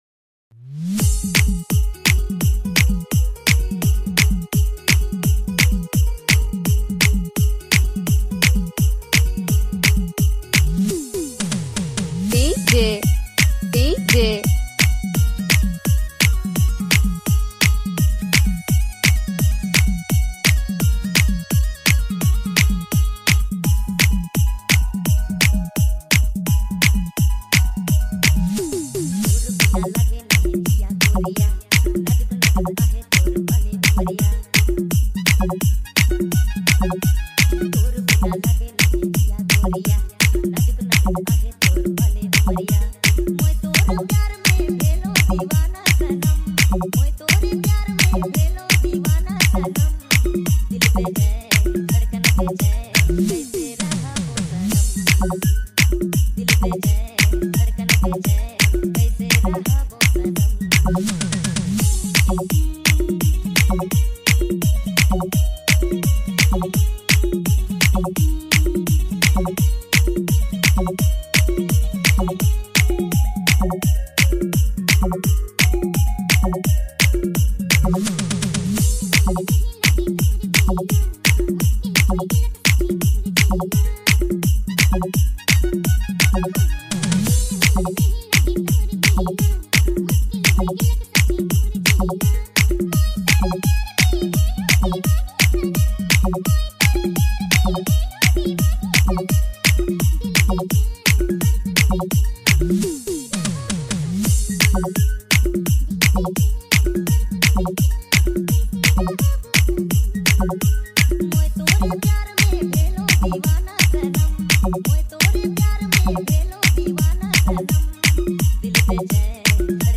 New Nagpuri Dj Song 2025